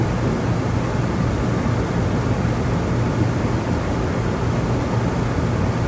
ambiances
storage.ogg